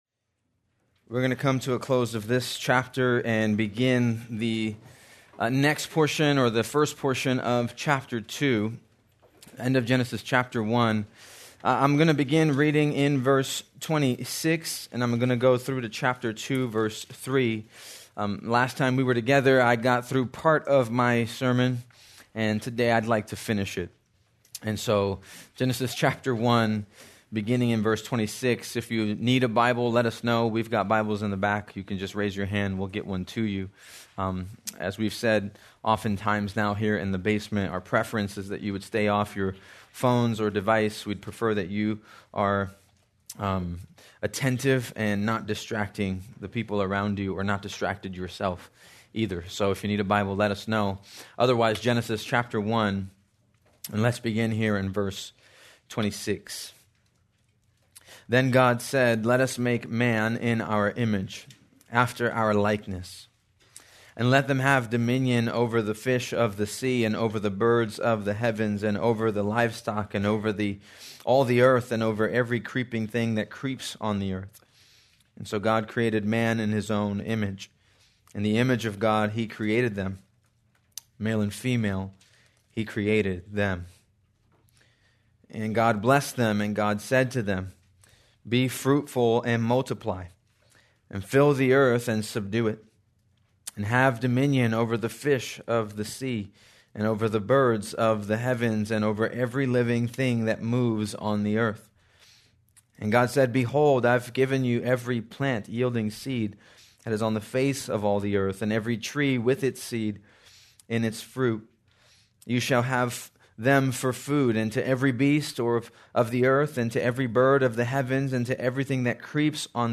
February 8, 2026 - Sermon
Please note, due to technical difficulties, this recording skips brief portions of audio.